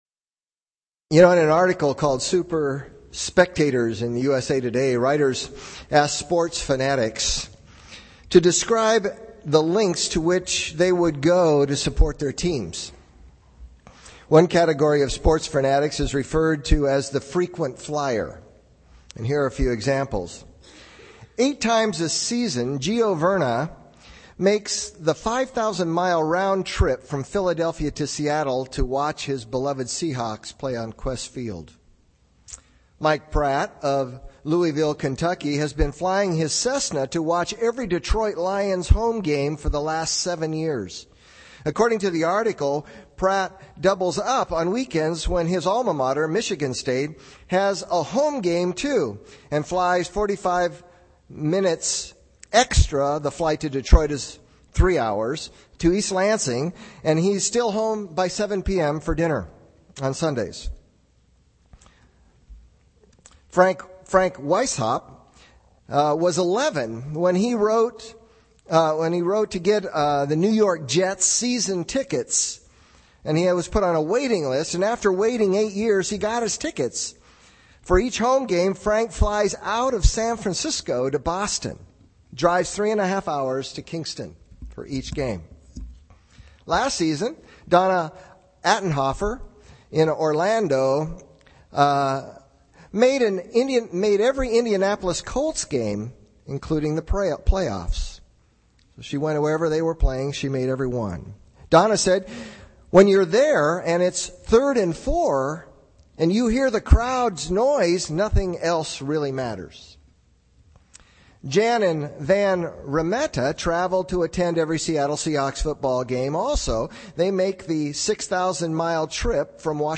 Theme: Genuine Conversion Produces Centralized Godly Commitments. Summary: read more ( categories: Acts | Sermons )